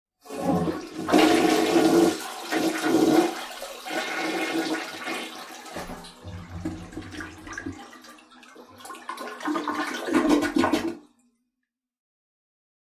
Toilet is flushed. Flush, Toilet Commode, Flush Urinal, Flush